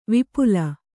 ♪ vipula